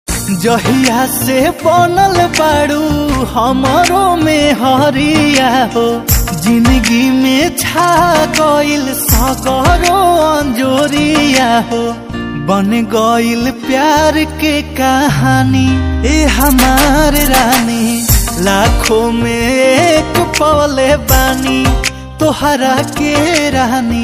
Bhojpuri Ringtones